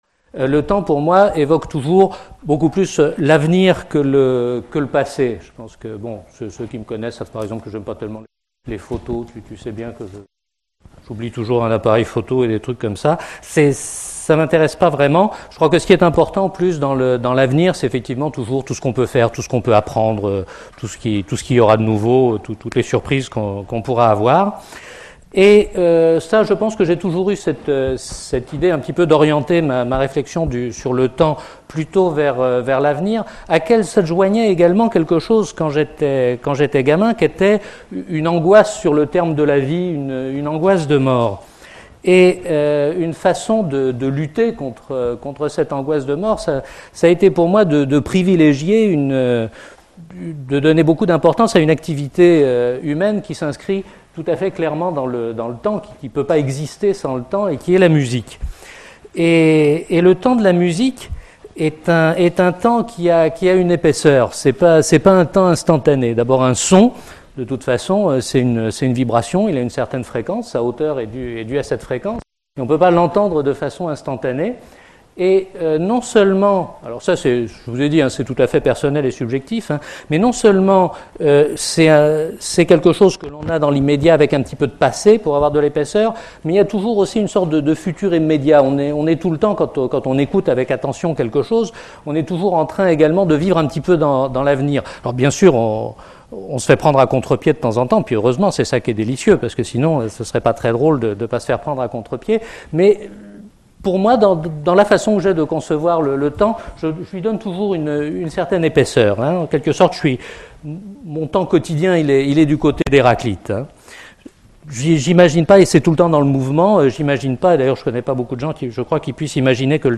Il démontre par le biais d’exemples concrets (Achille et la tortue, pile ou face, coureurs dans un stade) comment et à quel degré les sciences et plus particulièrement les mathématiques et la physique s’inspirent de la notion de temps pour élaborer leurs lois. La conférence a été donnée à l'Université Victor Segalen Bordeaux 2 dans le cadre du cycle de conférences "L'invité du Mercredi" / Saison 2003-2004 sur le thème "Demain".